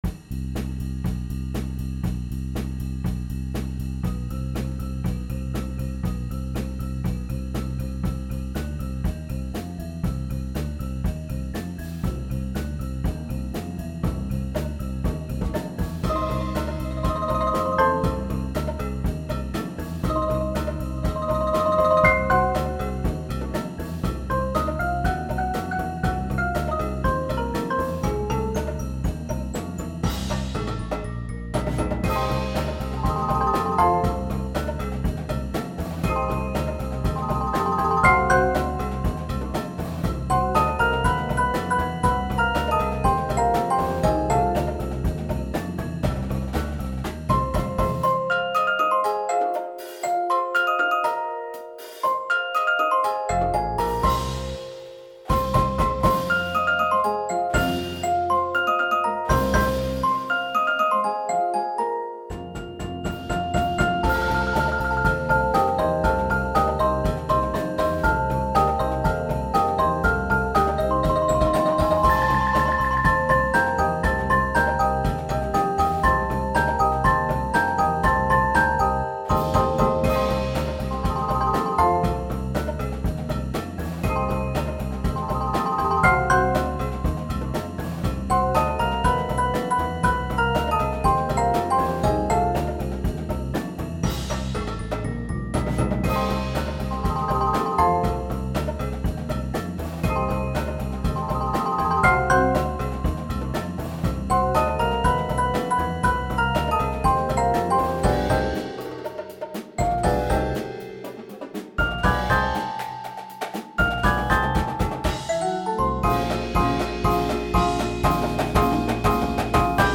Rubriek: Mallet-Steelband Muziek